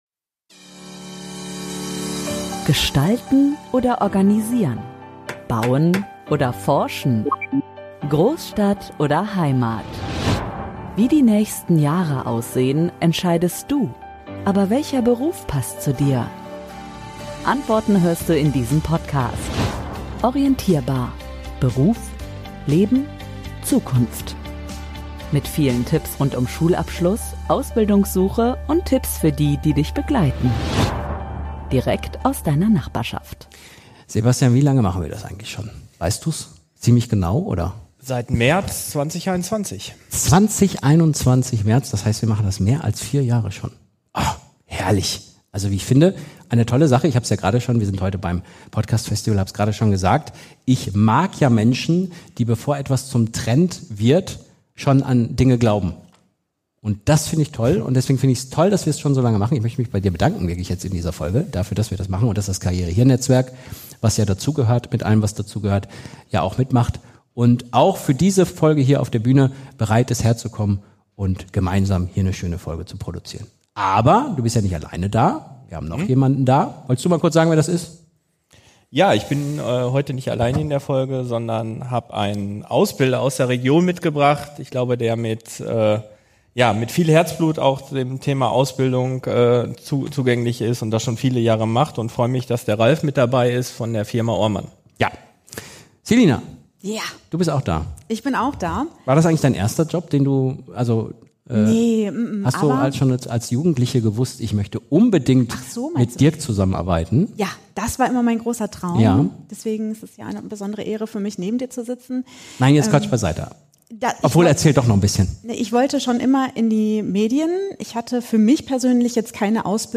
#60 Ausbildungsstart, Ausbilderrolle & Ausbildungseinblicke - LIVE vom Podcastfestival ~ ORIENTIERBAR Podcast